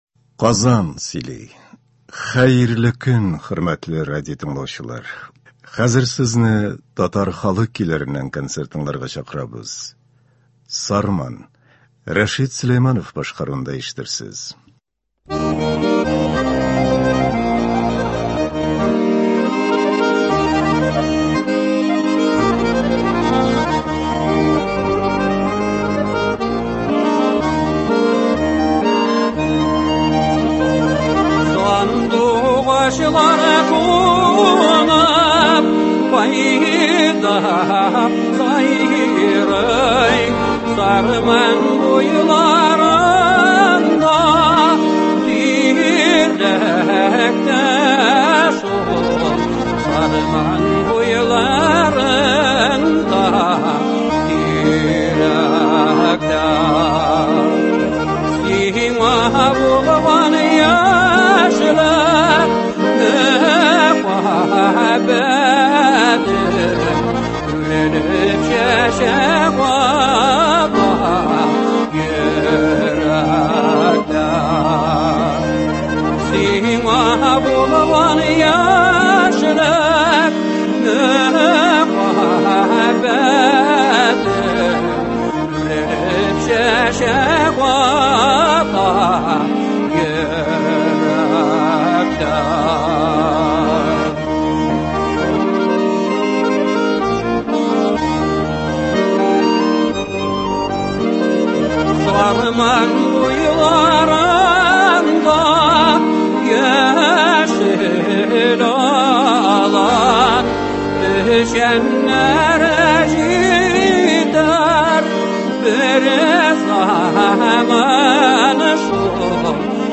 Татар халык көйләре (25.06.22)
Бүген без сезнең игътибарга радио фондында сакланган җырлардан төзелгән концерт тыңларга тәкъдим итәбез.